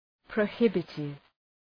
Προφορά
{prəʋ’hıbıtıv}
prohibitive.mp3